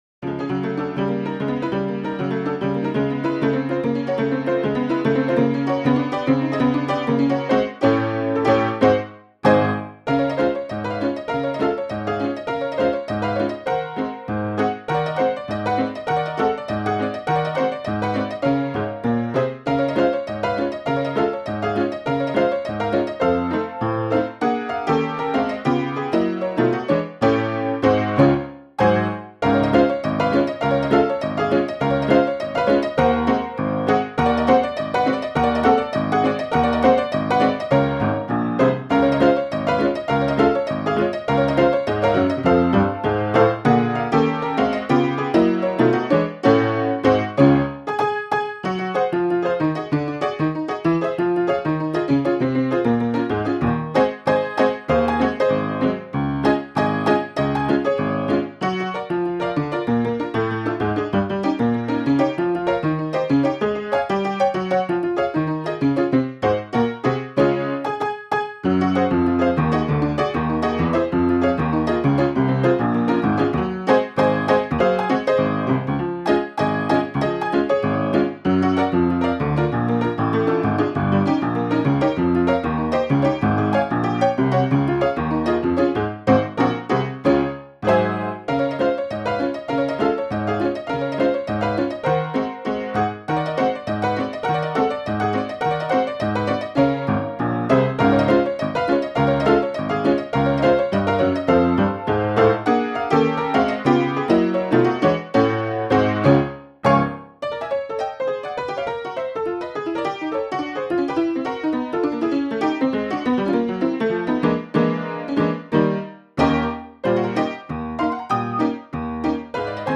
The 'old piano' available as a free download from Freepats sounded just right.
honky-tonk piano
The structure of 'Coaxing' is fairly typical of ragtime. An 8-bar introduction leads to the 16-bar main theme. The melody is played with the thumb while the other fingers play rapid triplet decoration above.
A second 16-bar theme with an accented melody staccato in the left hand follows and is repeated.
The trio is set in the dominant key and starts with a florid 8-bar intro.
I've concentrated on the main theme and set the tempo to be 85% of the MIDI file as issued which give a better feel of the melodic structure.
coaxing_piano_honky.m4a